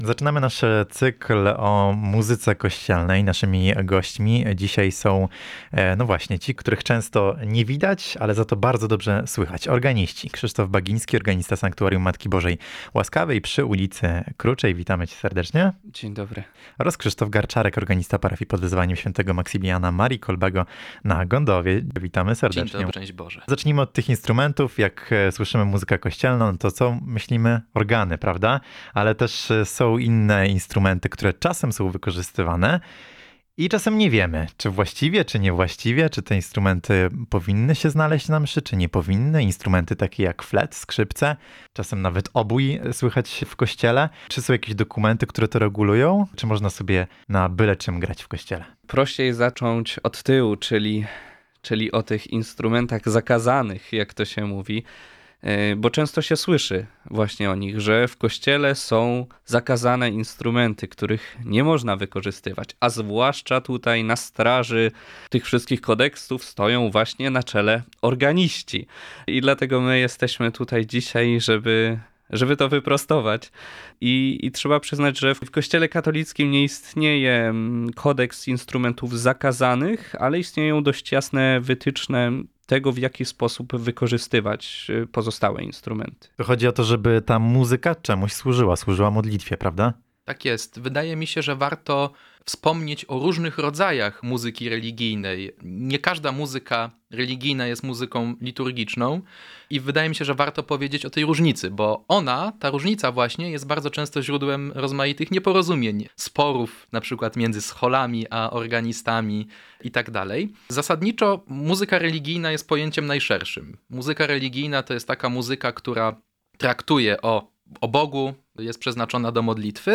organiści.